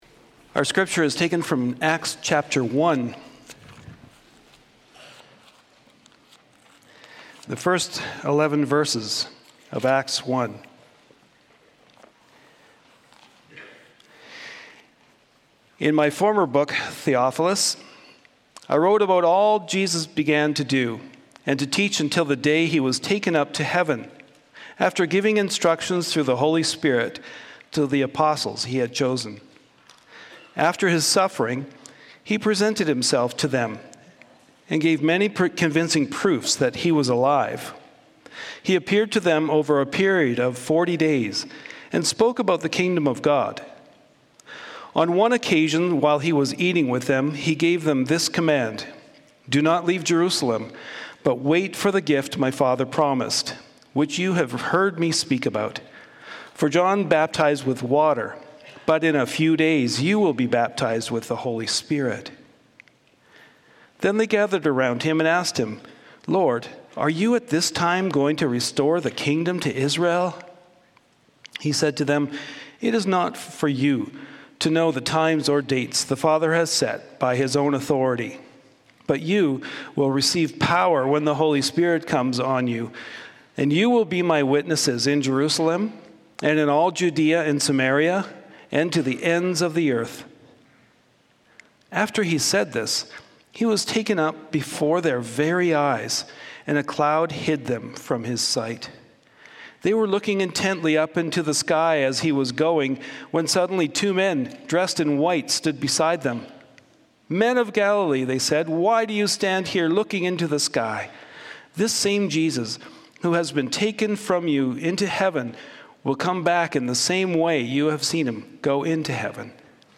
Sermons | Community Christian Reformed Church
Ascension Sunday